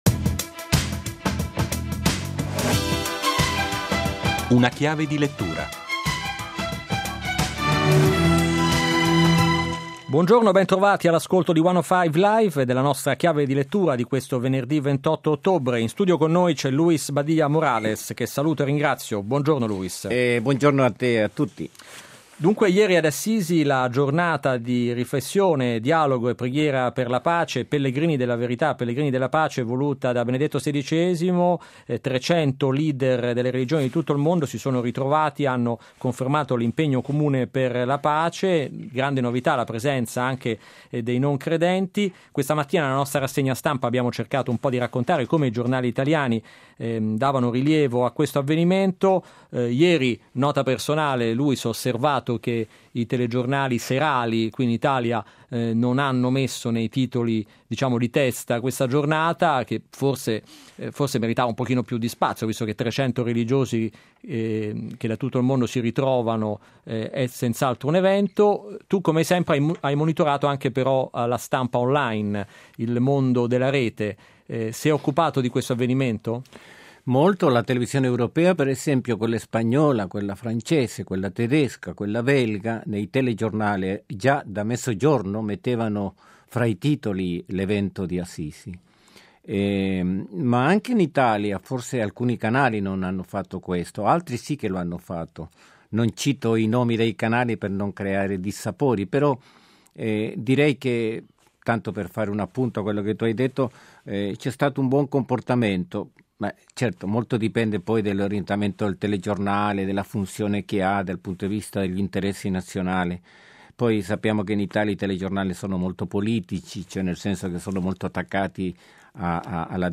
Inoltre, mentre gli incontri precedenti erano centrati sul tema della pace, negli interventi dei leader religiosi di quest'anno è prevalso il tema dell'importanza di scoprire la dignità dell'altro, a prescindere dalla sua etnia e dalla sua religione, per imparare a convivere con lui. (intervista